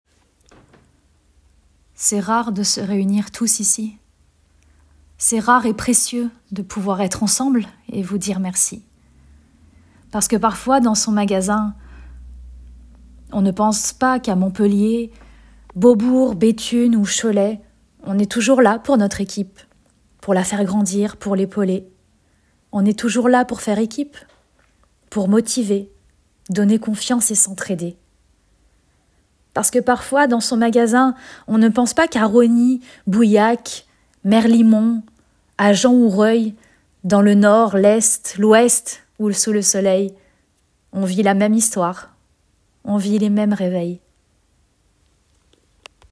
démo texte voix